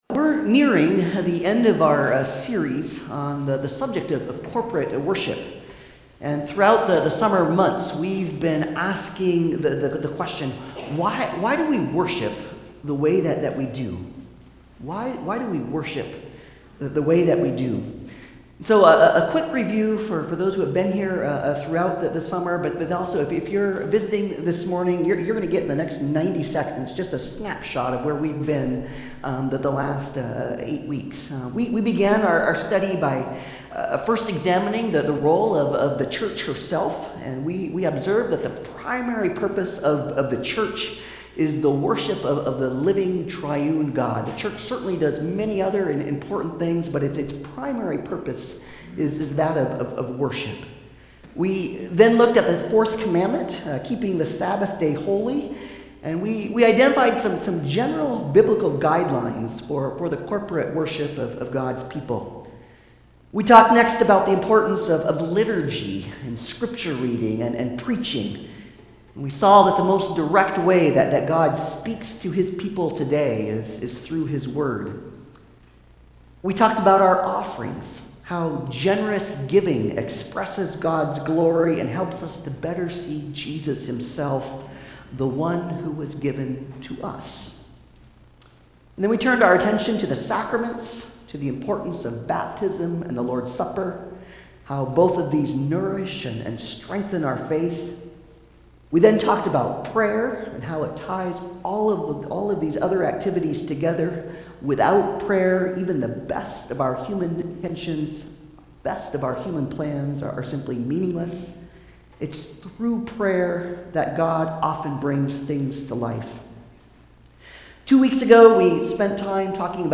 Created to Worship Passage: Romans 10:5-13, Ephesians 2:8-10, Hebrews 12:1-2 Service Type: Sunday Service « Music Benediction »